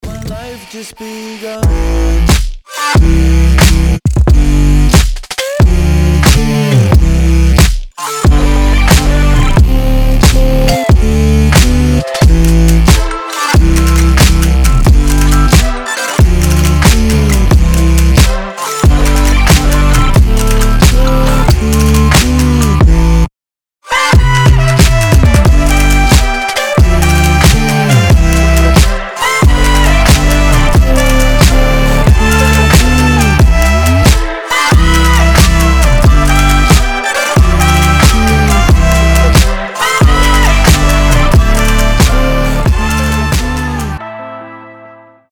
• Качество: 320, Stereo
атмосферные
мелодичные
Electronic
мощные басы
future bass
alternative
инди